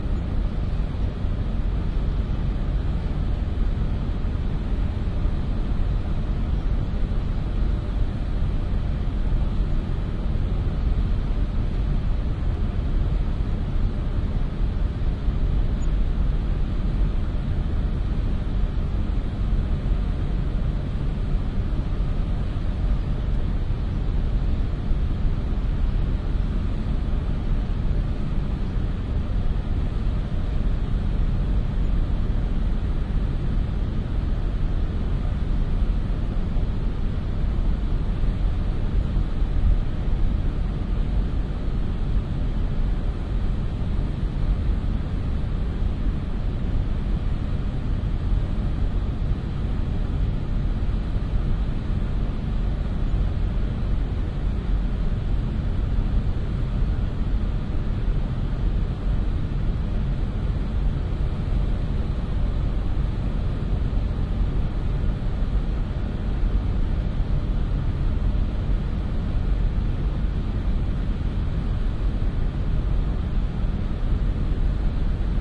现场录音 " 工厂外X之夜
描述：晚上在X工厂外面......。HIMD双耳录音。
标签： 背景噪声 双耳 现场录音 户外 通风
声道立体声